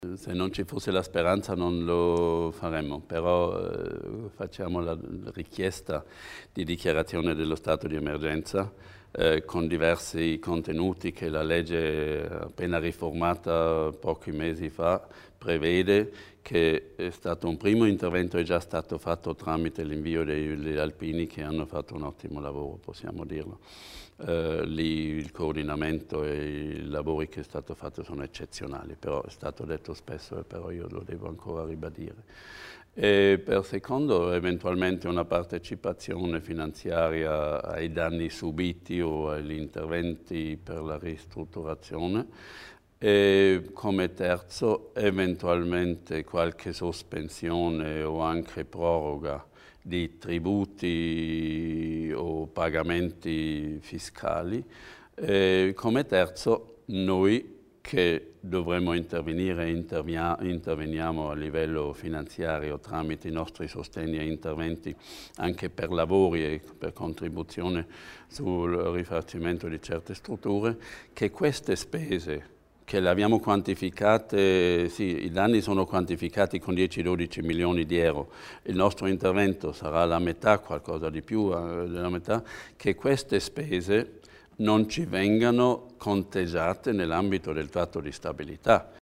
Il Vicepresidente Hans Berger spiega gli interventi a sostegno della Val di Vizze